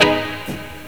RIFFGTR 15-R.wav